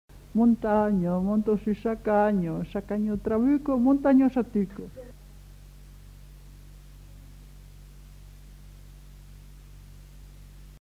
Aire culturelle : Haut-Agenais
Genre : forme brève
Effectif : 1
Type de voix : voix de femme
Production du son : récité
Classification : formulette